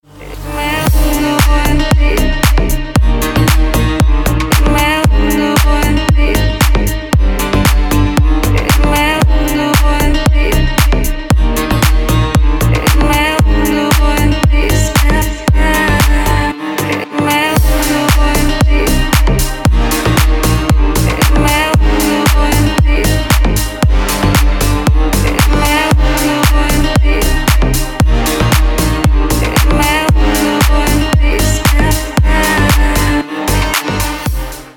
Категория: Deep House рингтоны